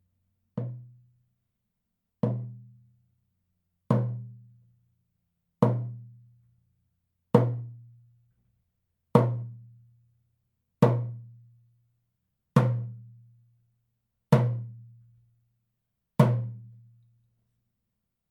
ネイティブ アメリカン（インディアン）ドラム NATIVE AMERICAN (INDIAN) DRUM 12インチ（deer 鹿）
ネイティブアメリカン インディアン ドラムの音を聴く
乾いた張り気味の音です 温度・湿度により皮の張り（音程）が大きく変化します